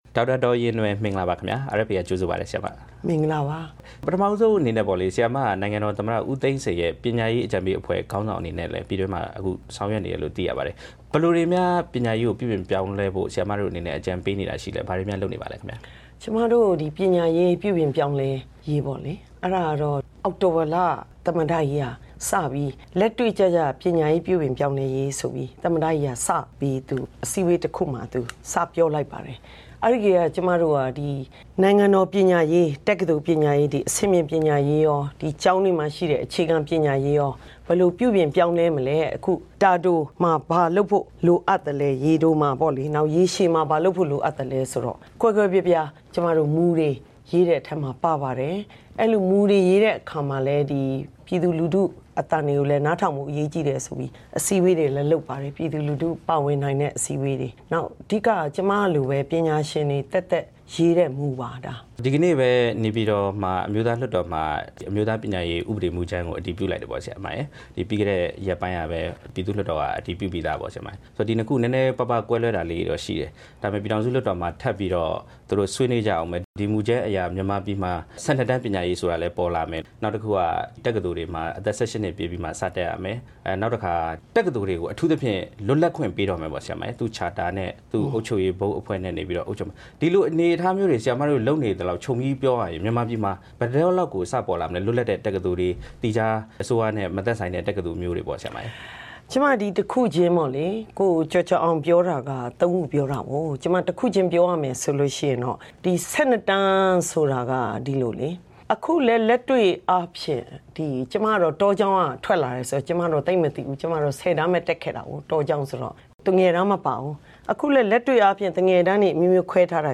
ပညာရေး ပြုပြင်ပြောင်းလဲရေး အကြံပေးခေါင်းဆောင် ဒေါက်တာ ဒေါ်ရင်ရင်နွယ်နဲ့ မေးမြန်းချက်
ဝါရှင်တန်ဒီစီမြို့တော် RFA ရုံးချုပ်မှာ